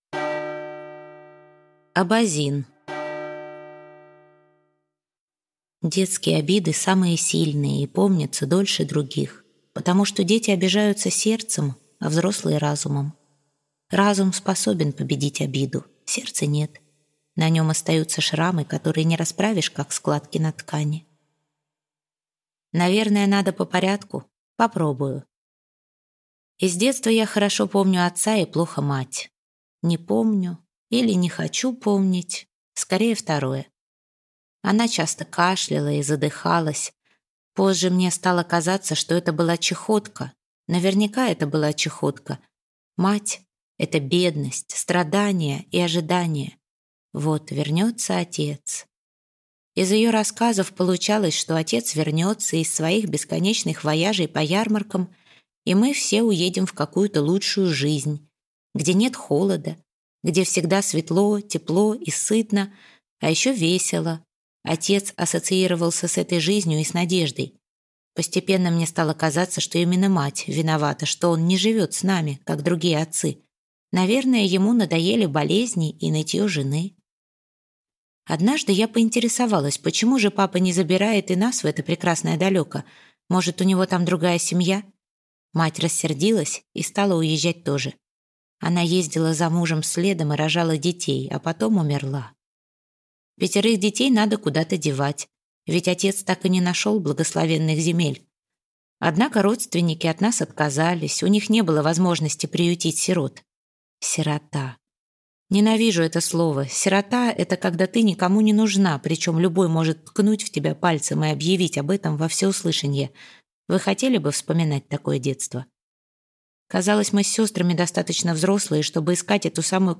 Аудиокнига Коко Шанель. Жизнь, рассказанная ею самой - купить, скачать и слушать онлайн | КнигоПоиск